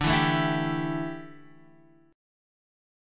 Dsus2sus4 chord